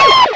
pokeemerald / sound / direct_sound_samples / cries / gible.aif